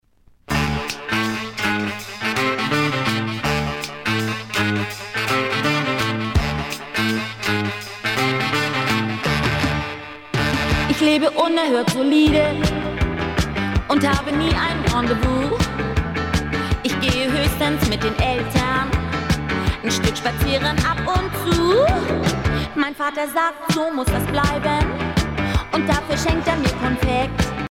danse : twist
Pièce musicale éditée